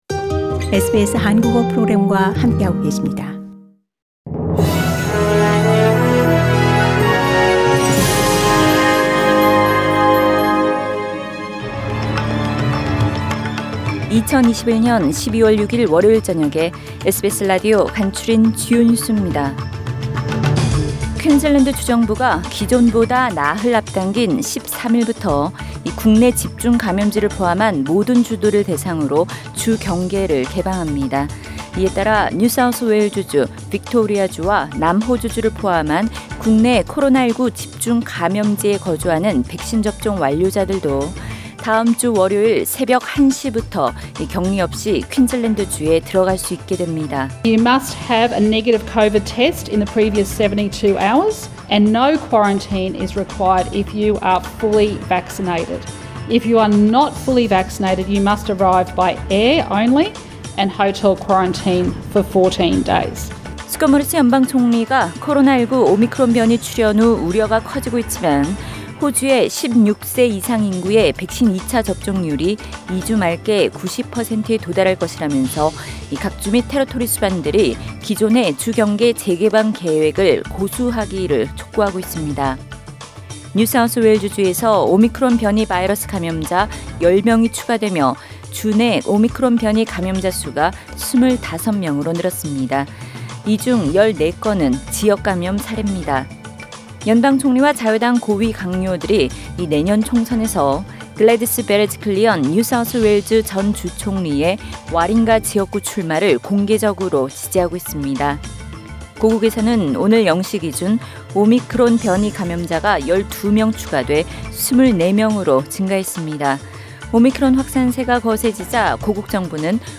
2021년 12월 6일 월요일 저녁의 SBS 뉴스 아우트라인입니다.